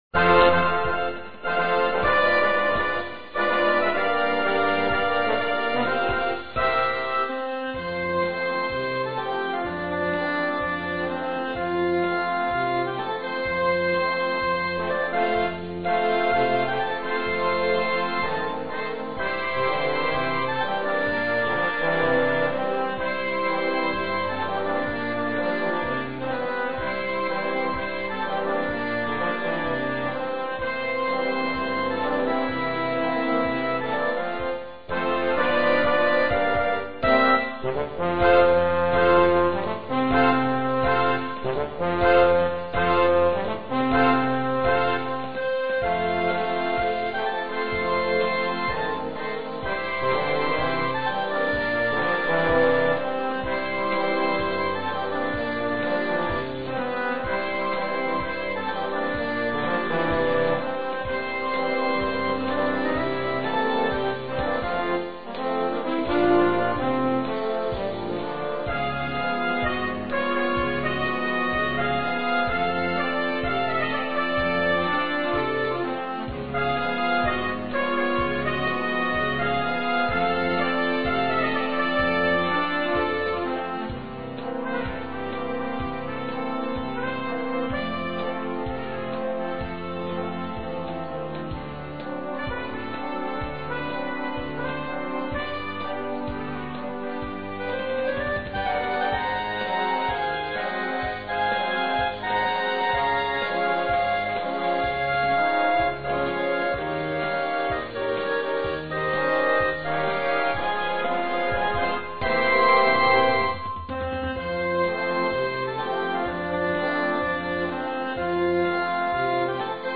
brani trascritti ed elaborati per banda